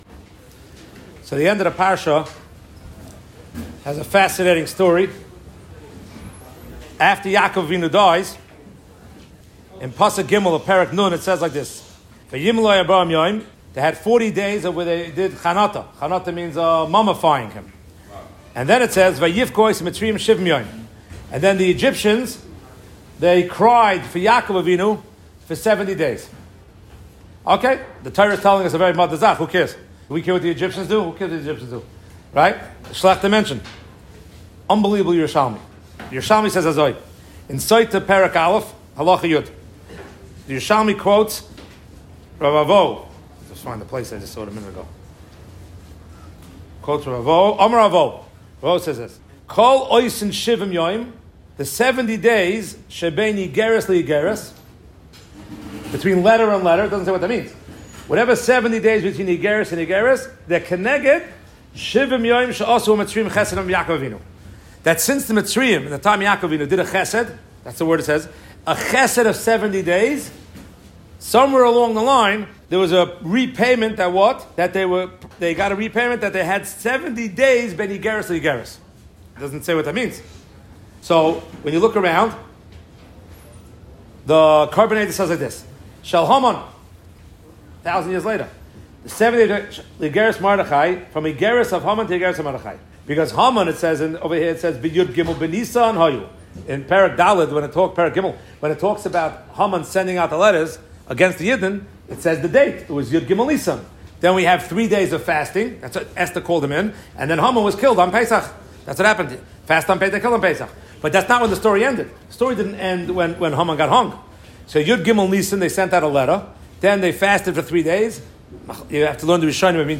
The Egyptians gave fake honor to Yaakov and the Jewish people were punished with a fake calamity. At Sasregan.